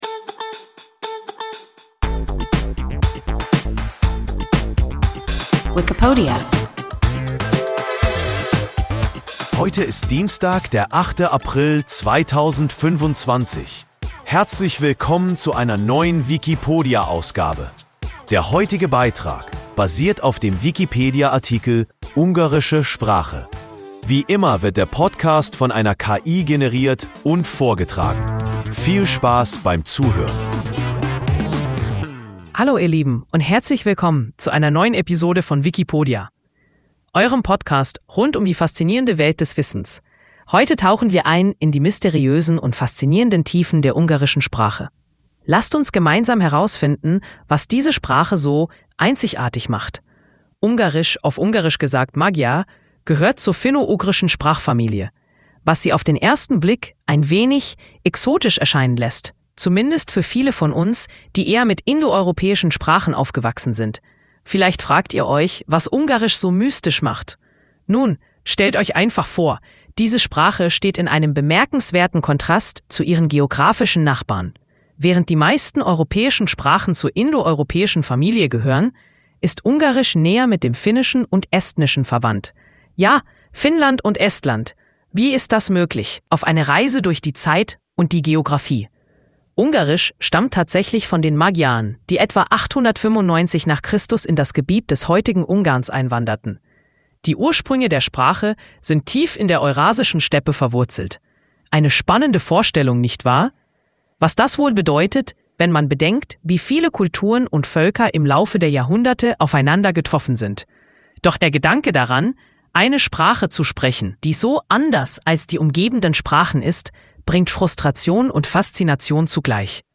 Ungarische Sprache – WIKIPODIA – ein KI Podcast